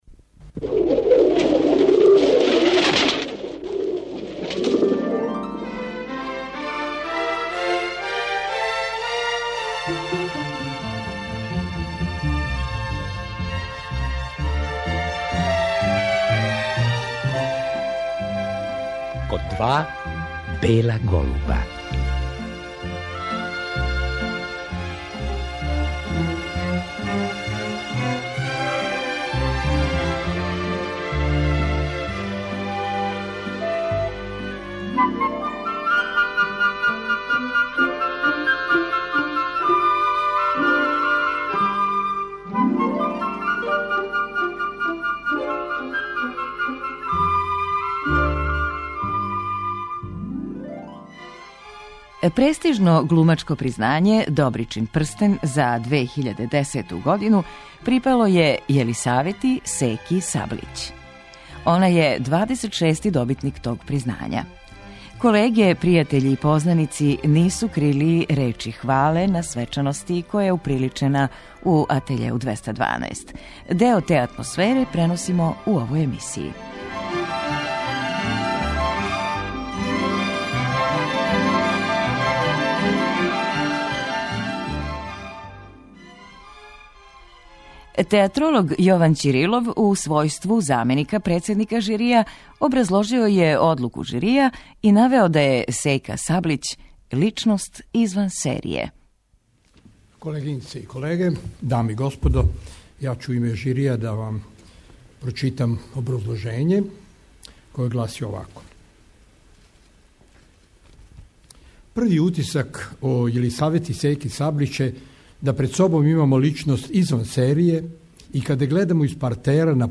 Свечаност је уприличена у београдском позоришту "Атеље 212". О Секи су говорили њене колеге, глумци, пријатељи и познаници.
Снимак са те вечери чућемо у вечерашњој емисији.